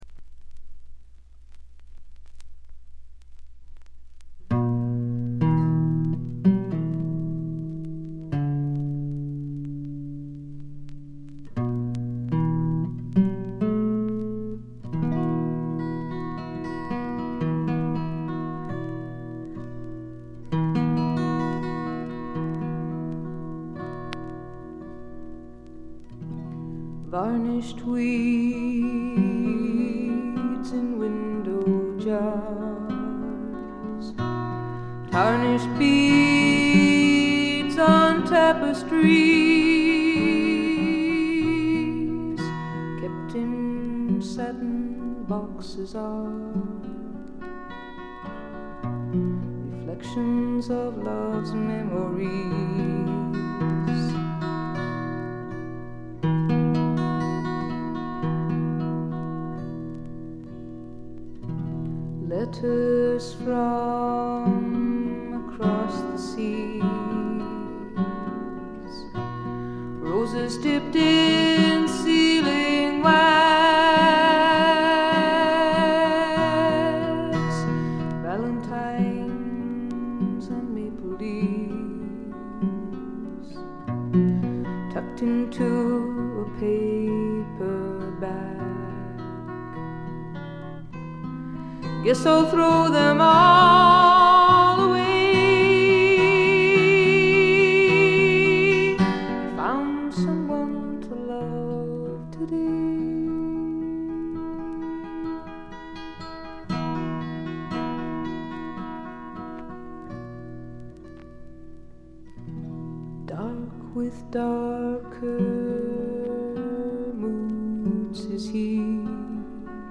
A1でチリプチがやや目立ちます。
これ以外は微細なバックグラウンドノイズ程度。
透明感のあるみずみずしさが初期の最大の魅力です。
女性フォーク／シンガーソングライター・ファンなら避けては通れない基本盤でもあります。
試聴曲は現品からの取り込み音源です。